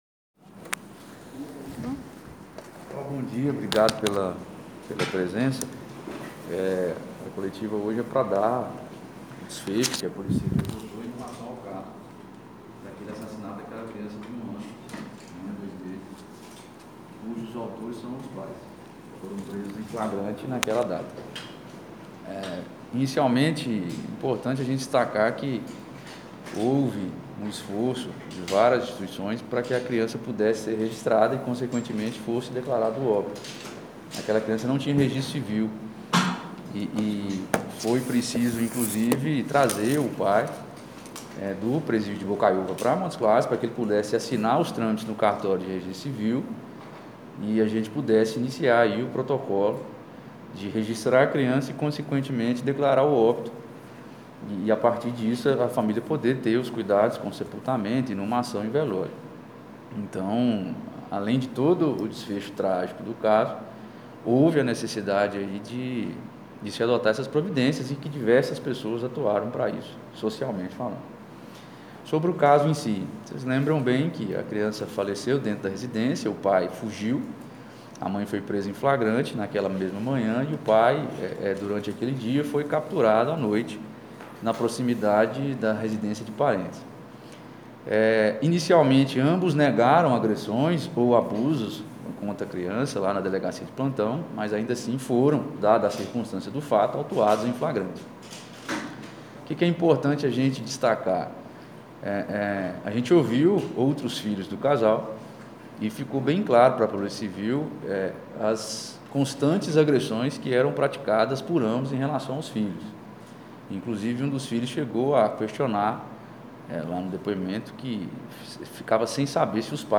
Coletiva de imprensa